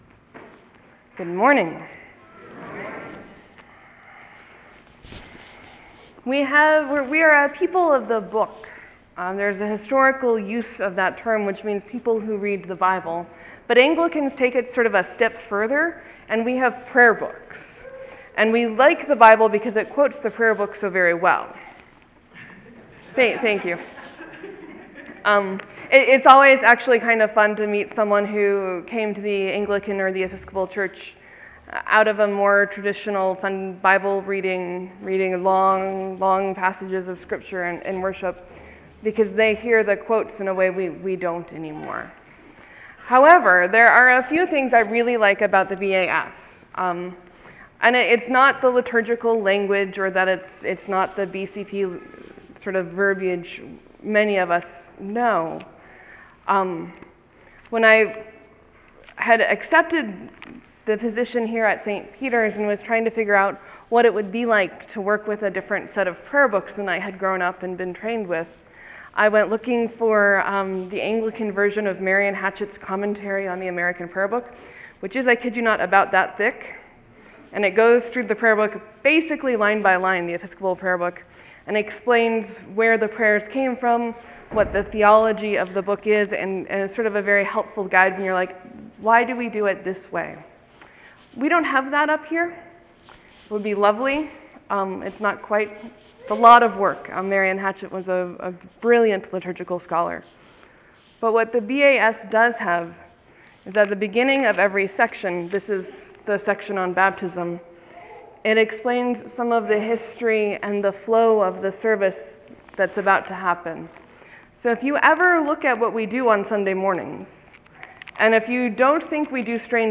Morsels and Stories: Resources in the BAS Sermon: Dancing Foolishly Today we hear a snippet of David's story.